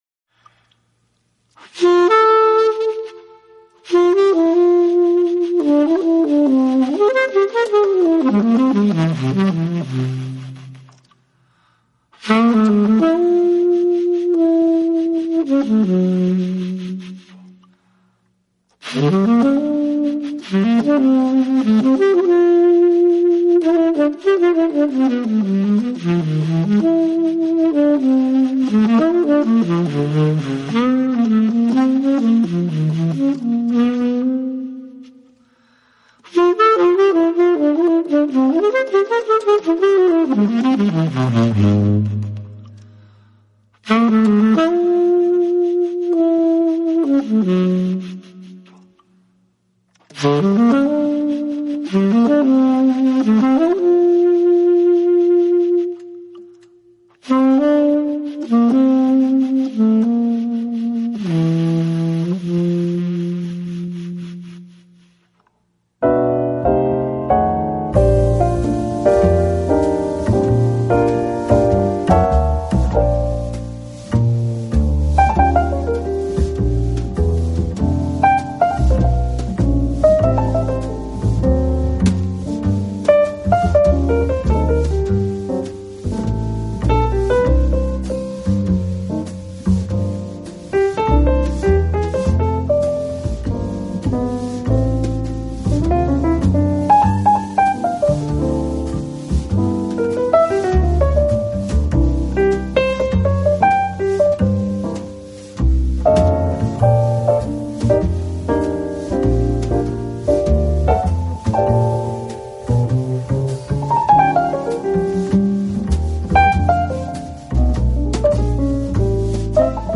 piano
bass
drums
音乐类型：Jazz
辑闲适淡雅，旋律优美，如月光一样轻柔，桂花一样幽香，红酒一样微醺，非常